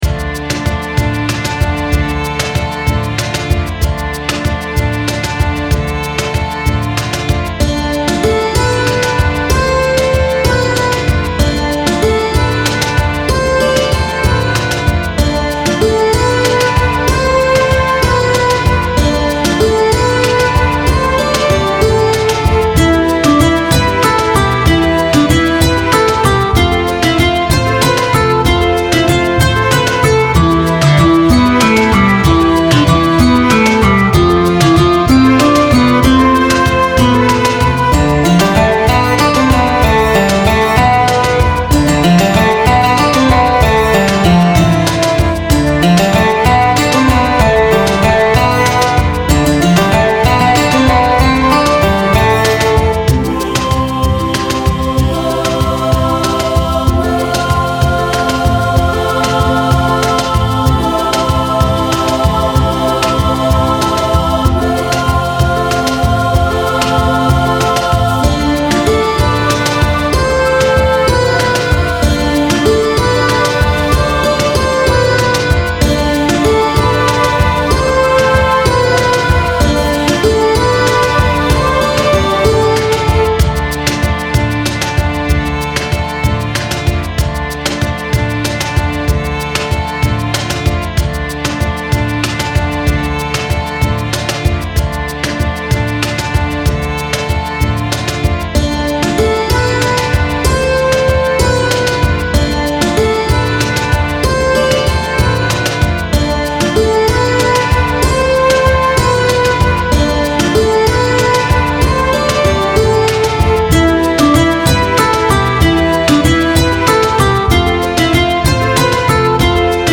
ゲームに登場する砂漠のダンジョンの様なイメージの3拍子の曲です。